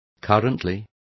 Complete with pronunciation of the translation of currently.